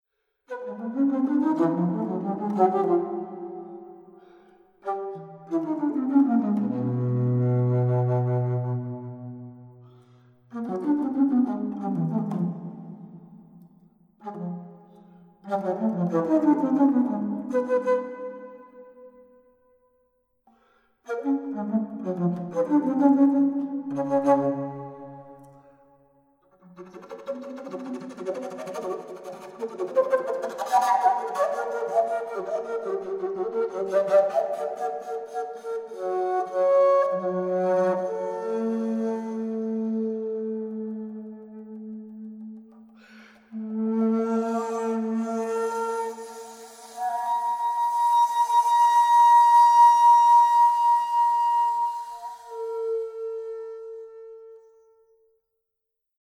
Australian classical music